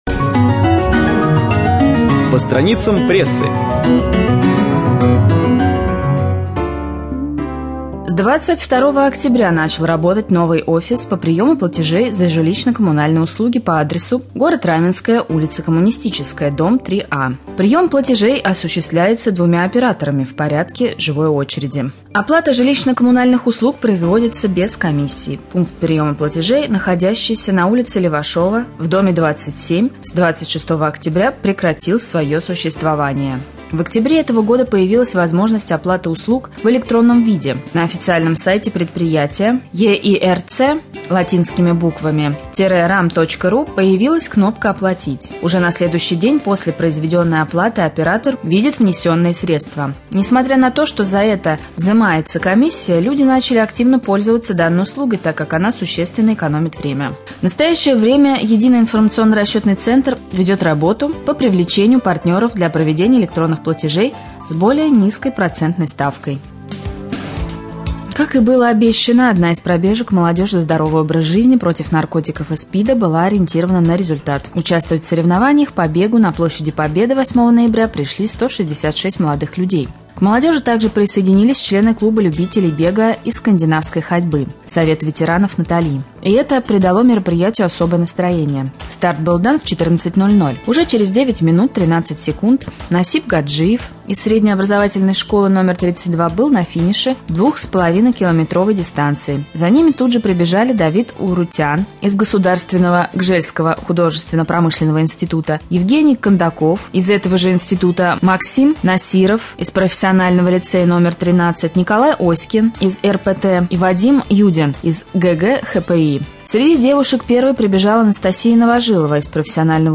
1.Новости